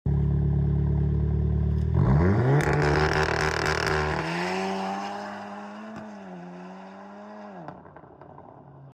Pure Launch Control Sound🔥💥 Sound Effects Free Download
Pure launch control sound🔥💥